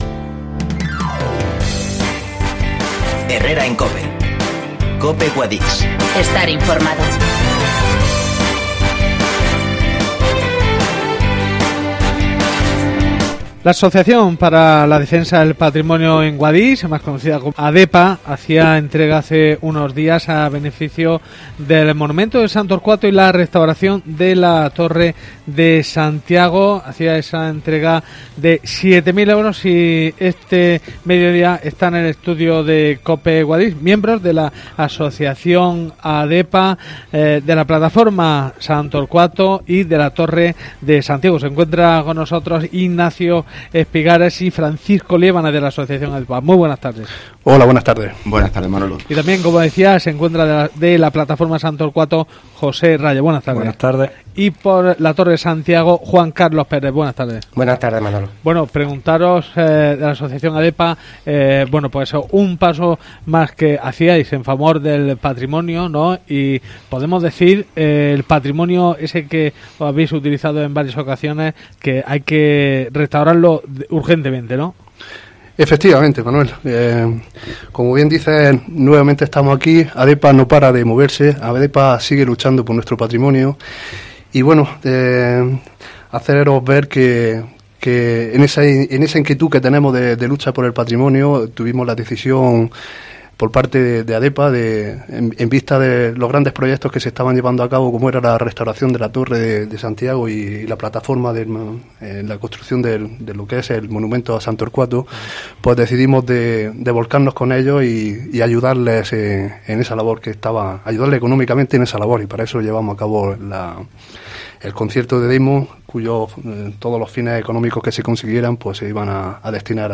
Entrevista COPE Guadix :ADEPA hace entrega de 7.000 € para el Patromonio de Guadix